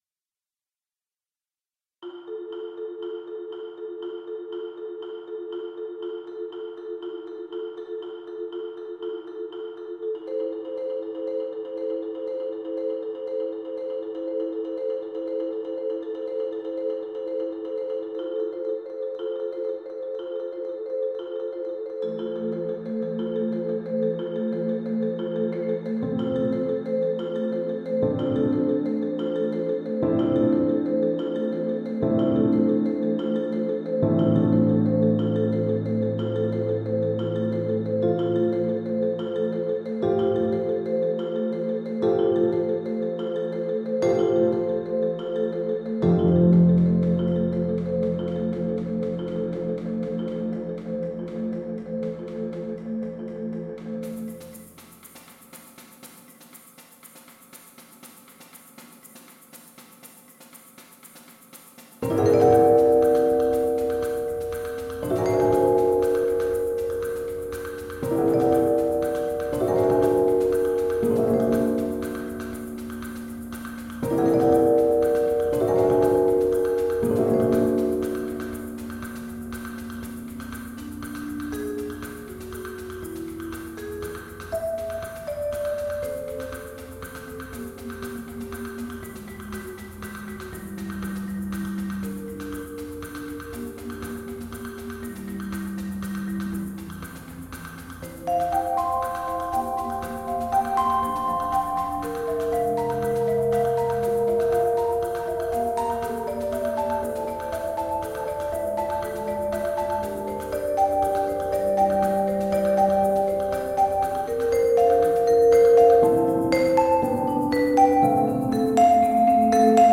"Minimalism" - Chamber Music
I attempted a minimalist work. I've always been intrigued with this genre, and really get into the "trance"-like grooves that the rhythms create.